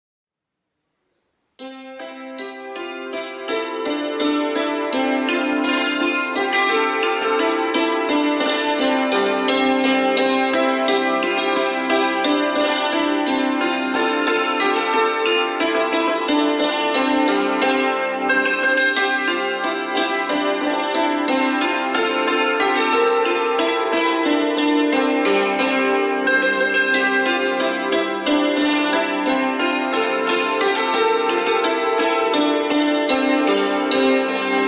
cymbaly.mp3